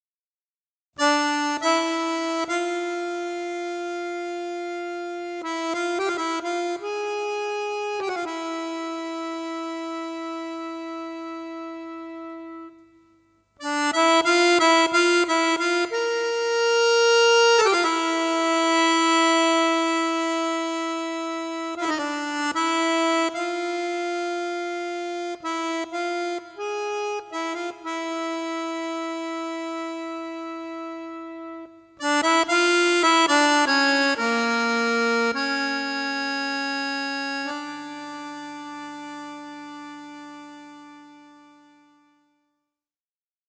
voce, percussioni, tastiere, lira macedone
voce, duduk, saz, pianoforte, chitarra
Violino
violino, viola
Violoncello
chitarra acustica ed elettrica
Fisarmonica
Sax alto
Tuba, trombone